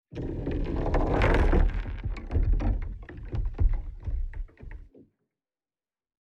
Звуки заморозки